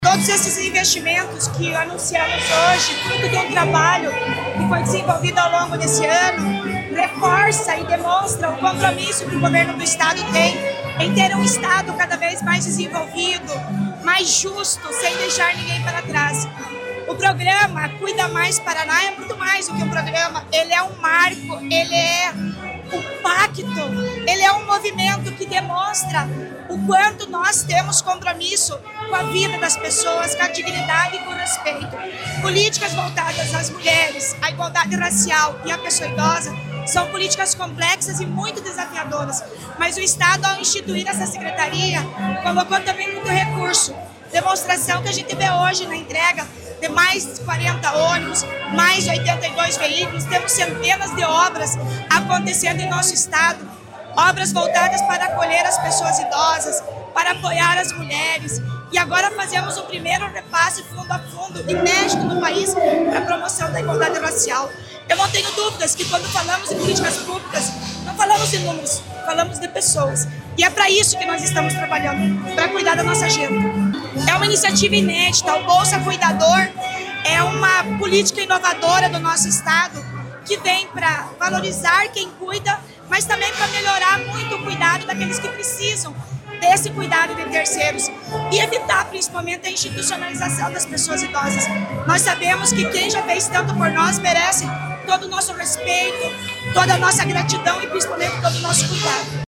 Sonora da secretária da Mulher, Igualdade Racial e Pessoa Idosa, Leandre Dal Ponte, sobre as ações para fortalecer a igualdade racial e a política da pessoa idosa no Estado | Governo do Estado do Paraná